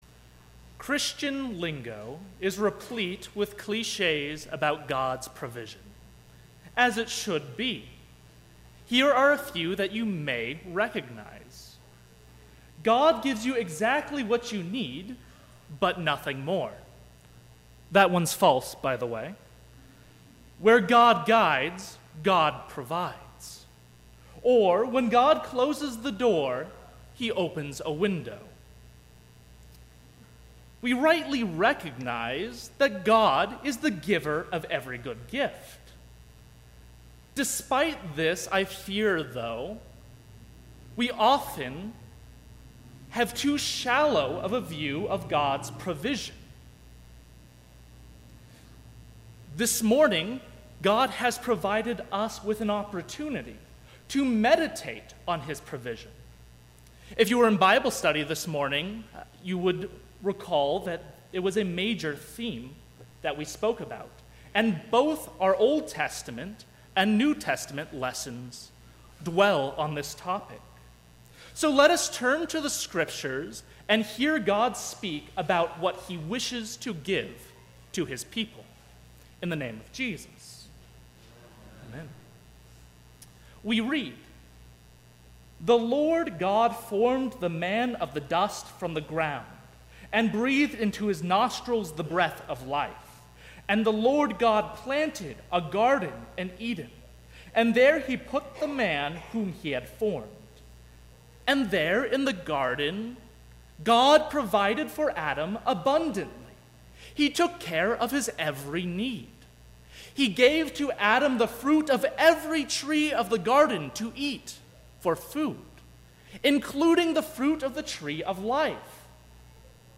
Seventh Sunday after Trinity
Sermon – 7/30/2017